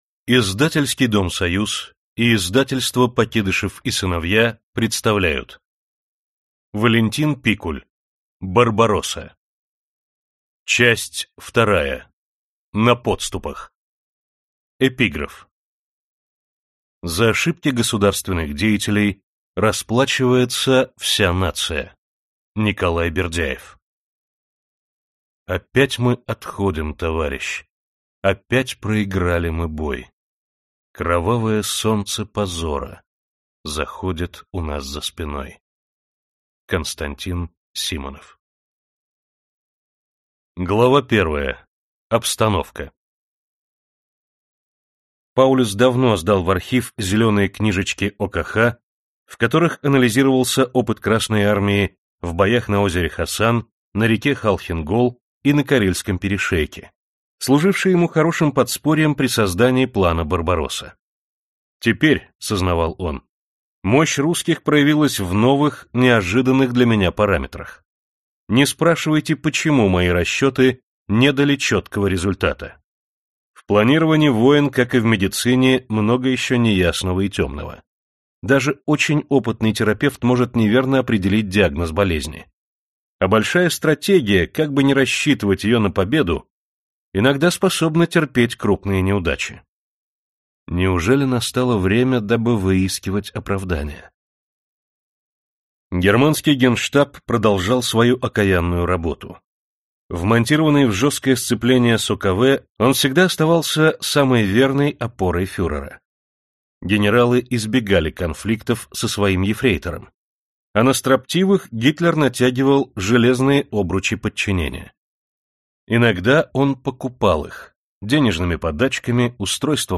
Аудиокнига Барбаросса. Часть 2. На подступах | Библиотека аудиокниг